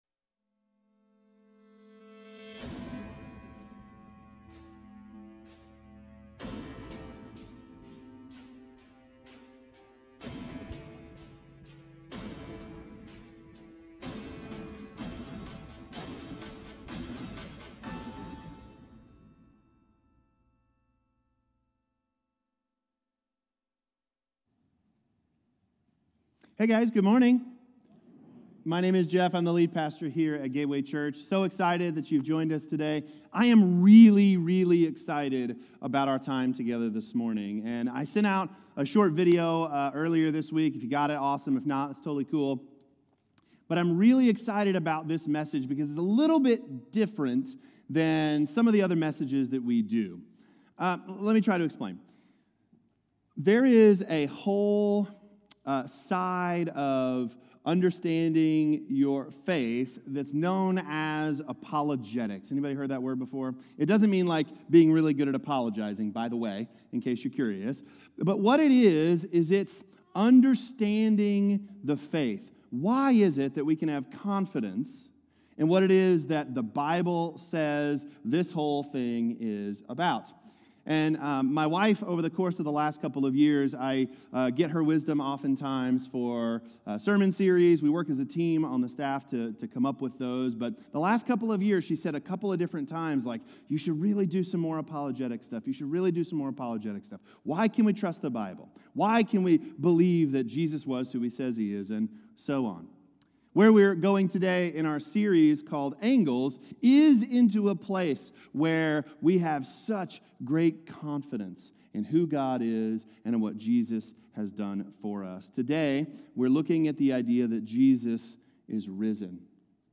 Jesus-is-Risen-Sermon-2.16.25.m4a